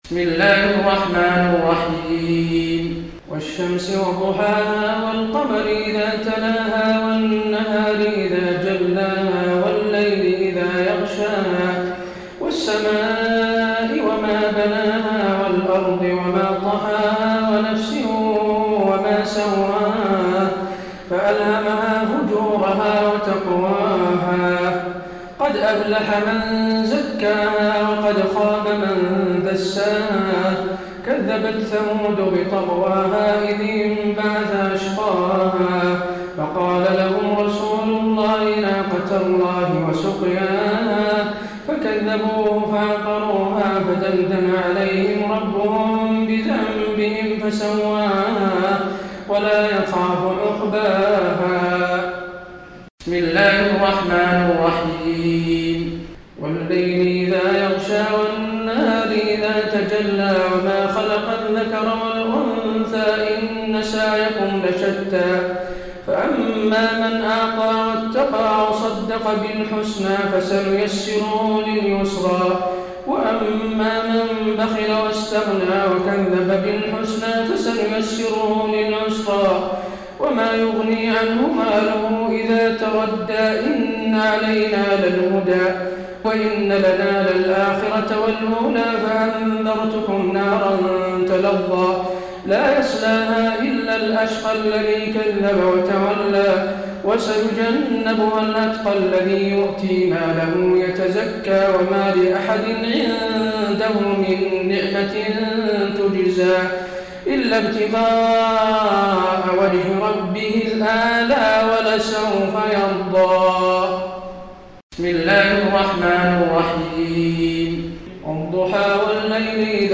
تراويح ليلة 29 رمضان 1433هـ من سورة الشمس الى الناس Taraweeh 29 st night Ramadan 1433H from Surah Ash-Shams to An-Naas > تراويح الحرم النبوي عام 1433 🕌 > التراويح - تلاوات الحرمين